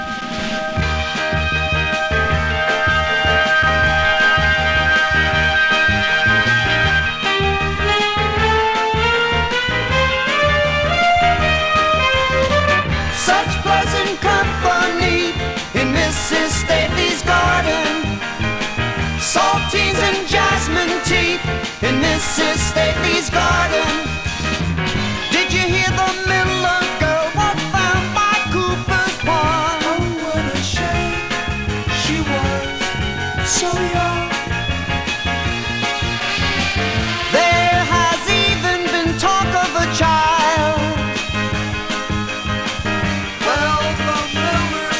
Rock, Pop, Psychedelic Rock　USA　12inchレコード　33rpm　Stereo